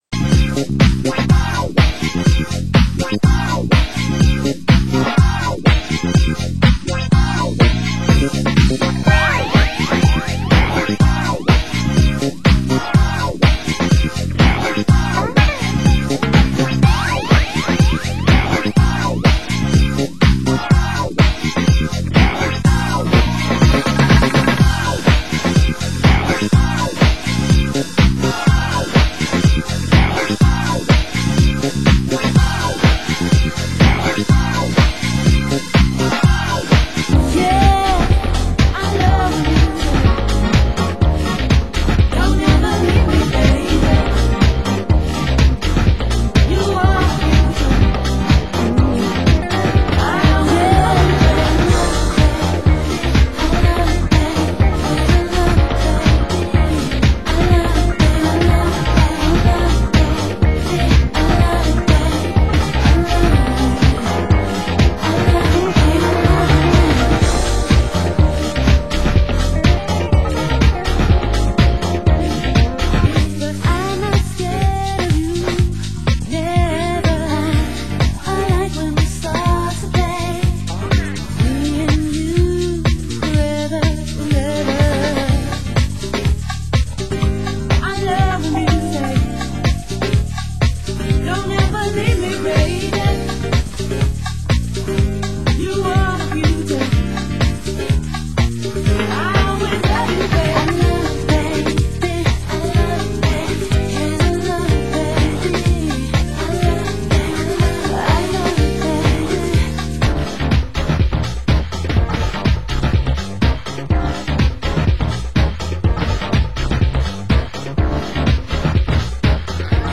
Format: Vinyl Double 12 Inch
Genre: Euro House